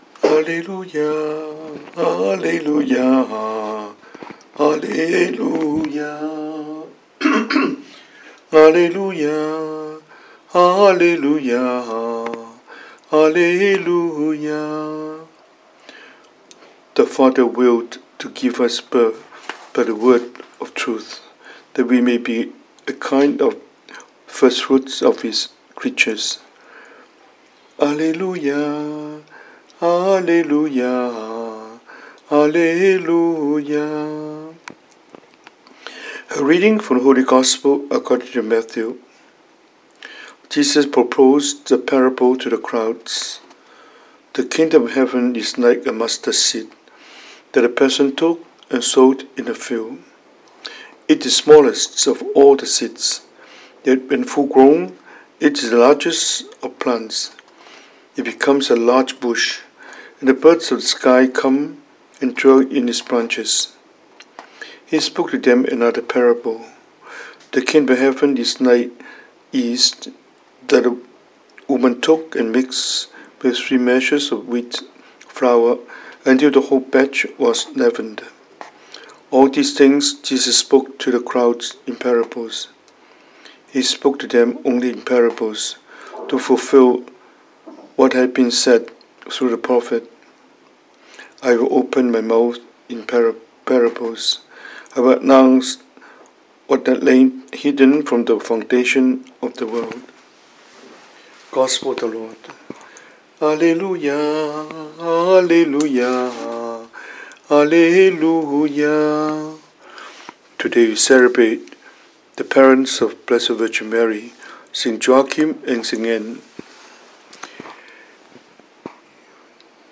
英文講道